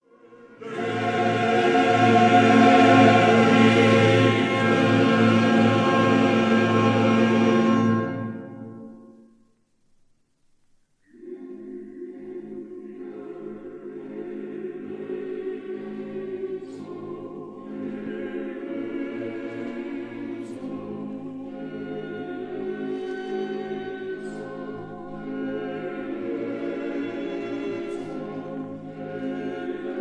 soprano
contralto
tenor
bass